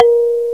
Index of /m8-backup/M8/Samples/Fairlight CMI/IIX/PERCUSN1